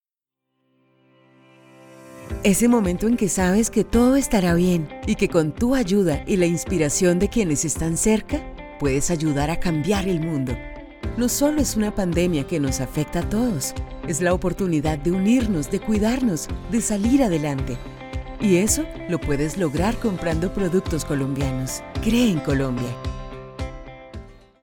VO IN SPANISH
Home recording studio. Professional Rhode microphones for excellent quality.